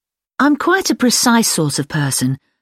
So, after the elision, it sounds just like a single schwa: /ə/.
ǀ ˈsɔːt ə ˈpɜːsən ǀ